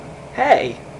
Hey Sound Effect
Download a high-quality hey sound effect.